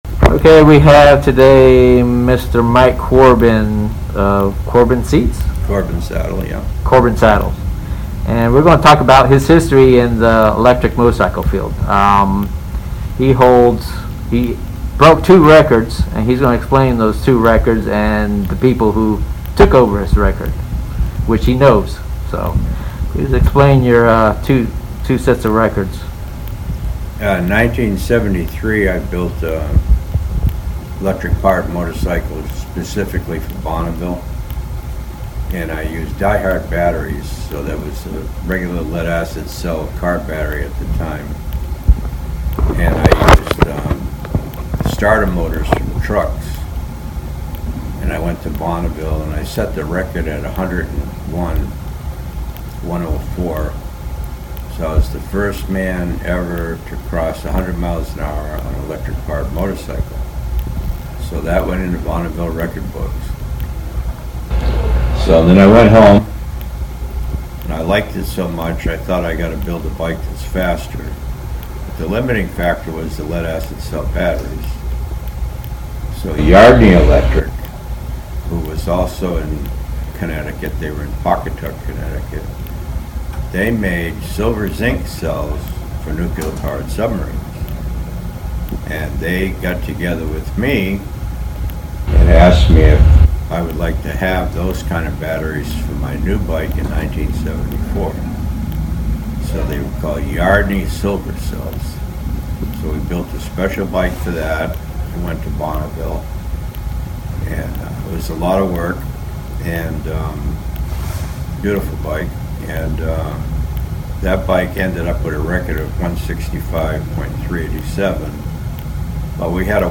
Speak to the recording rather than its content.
turn up sound to maximium to hear we will separate the audio from the video and boost the sound to you can normally hear the audio/interview